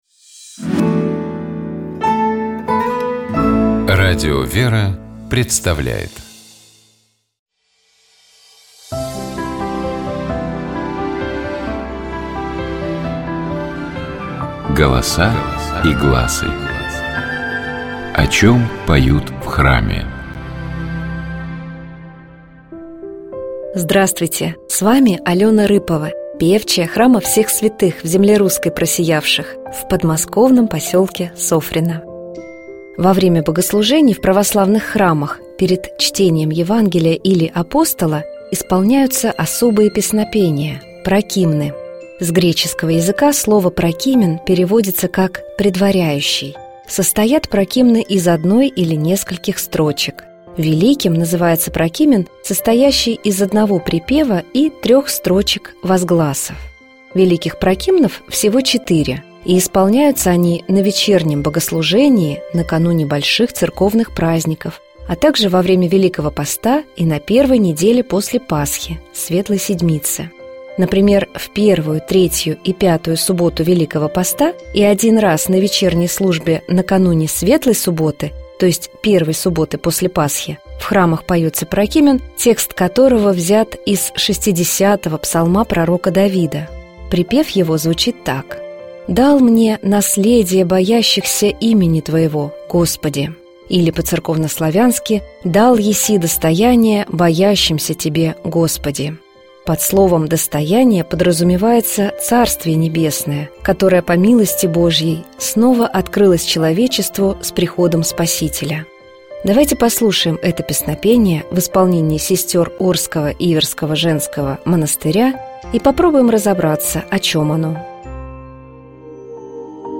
Golosa-i-glasy-Dal-esi-Velikij-prokimen.mp3